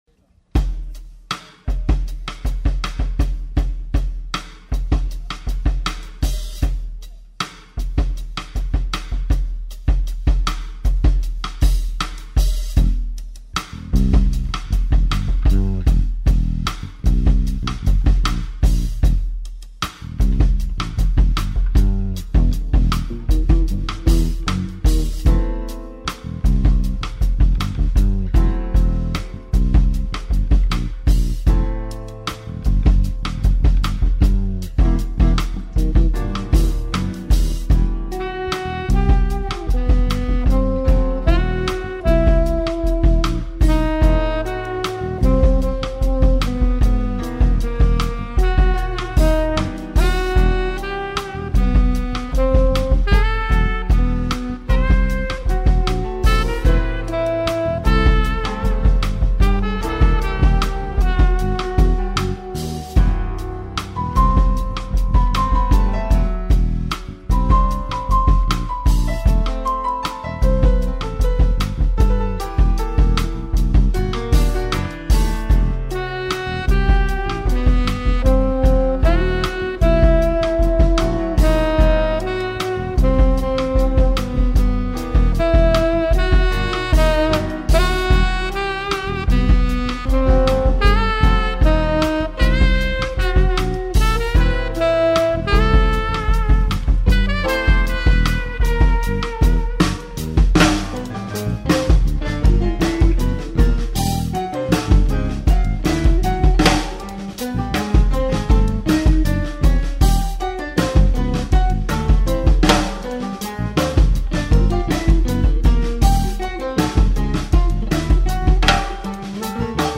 (Note: OK sound quality, guitar hard to hear)